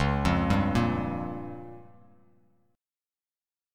C#6b5 chord